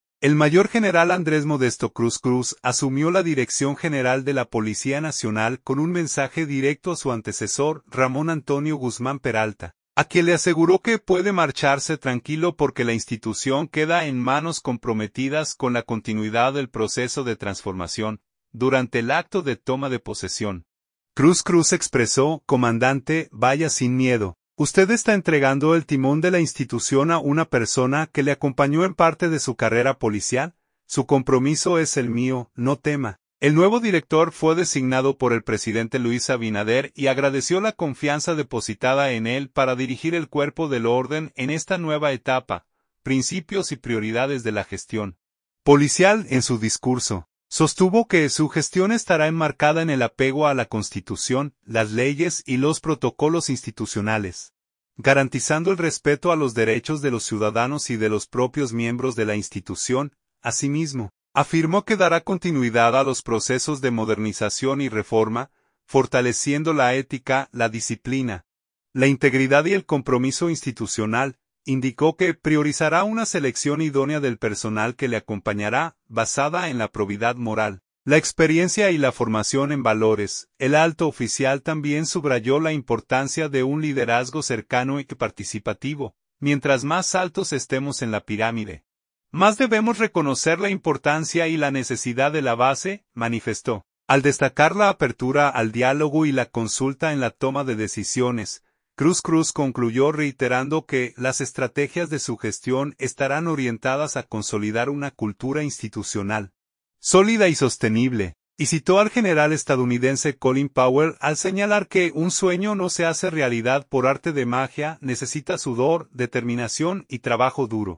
Durante el acto de toma de posesión, Cruz Cruz expresó: "Comandante, vaya sin miedo. Usted está entregando el timón de la institución a una persona que le acompañó en parte de su carrera policial. Su compromiso es el mío, no tema".